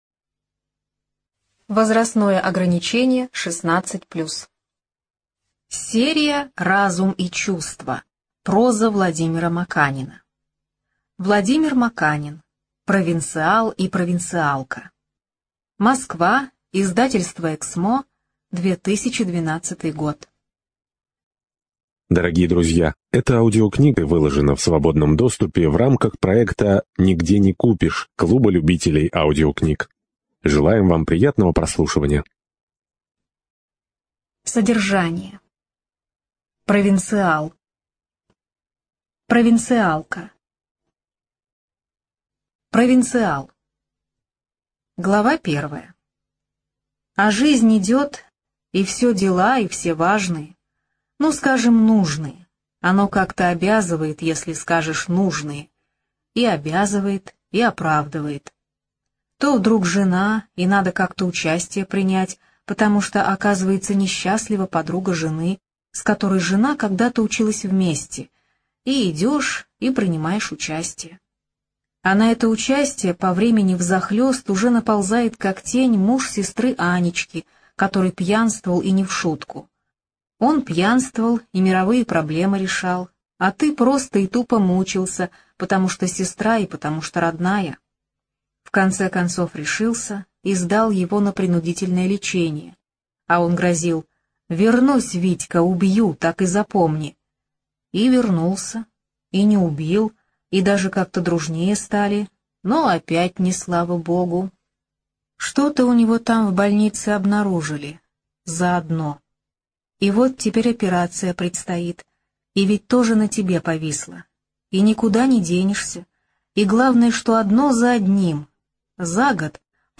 ЖанрСовременная проза
Студия звукозаписиКемеровская областная специальная библиотека для незрячих и слабовидящих